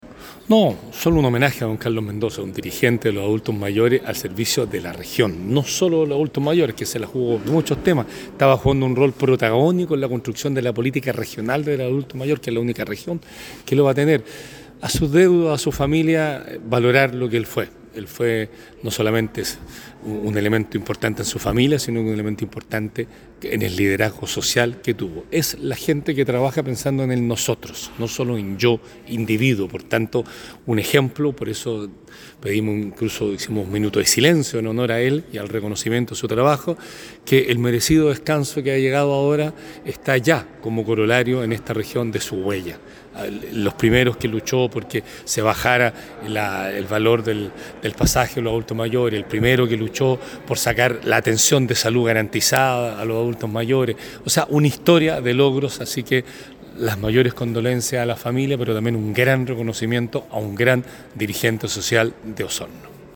En el inicio de la sesión plenaria nº17 del Consejo Regional de Los Lagos, que se desarrolla este miércoles en la ciudad de Puerto Montt
El Gobernador de la región de Los Lagos, Patricio Vallespin